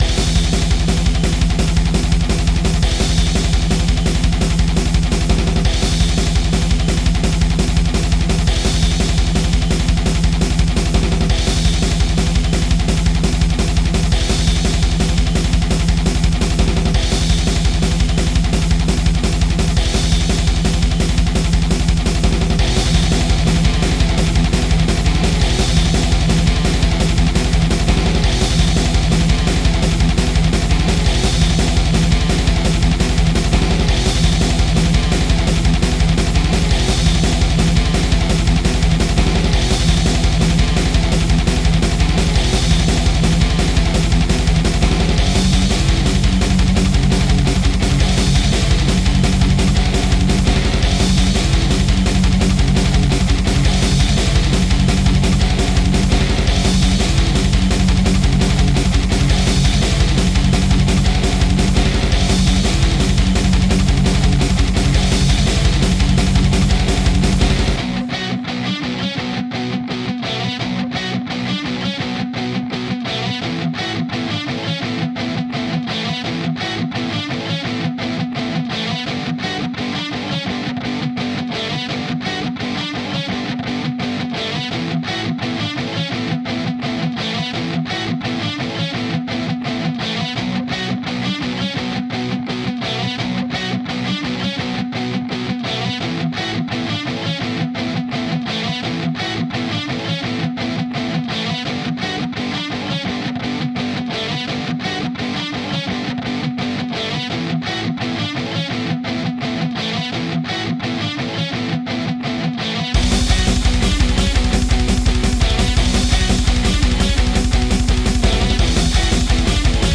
Всё на компе сделал